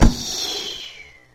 Descarga de Sonidos mp3 Gratis: flecha 1.